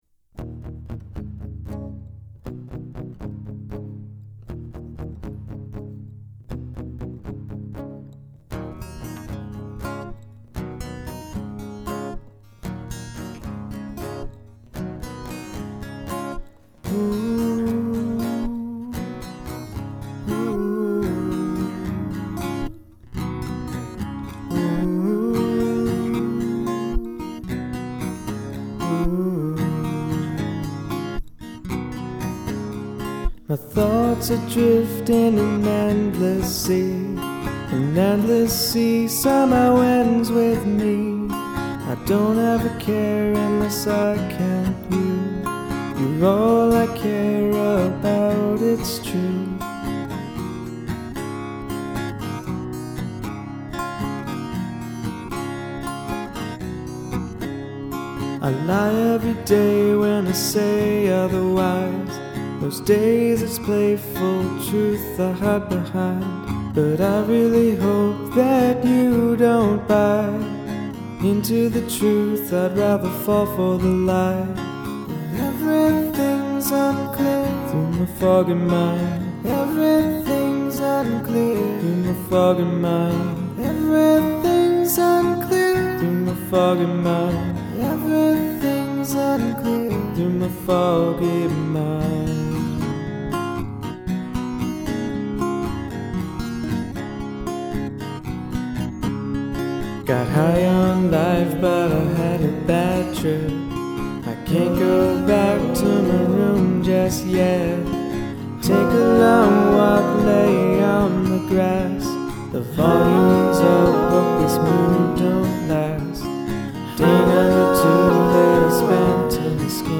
Auto Tune
"Lifeboats & Anchors" as the background vocal.